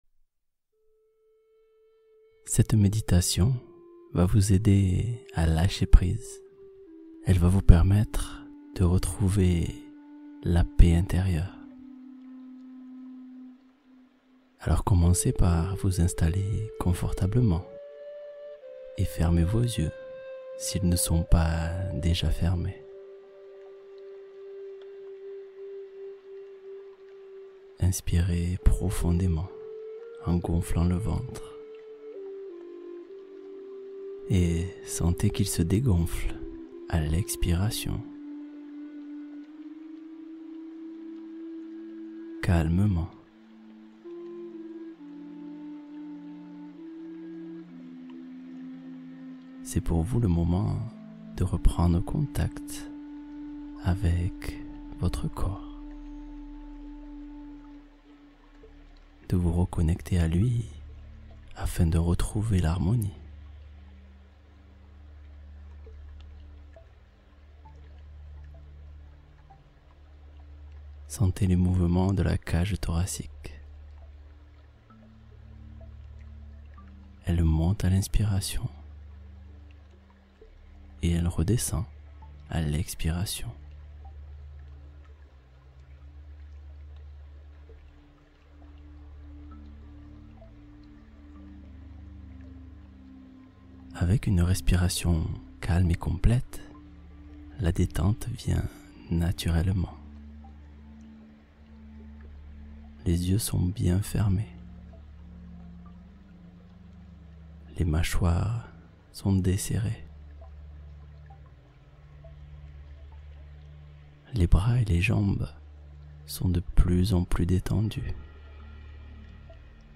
Méditation du cœur au matin — Se relier à son essence profonde dès le réveil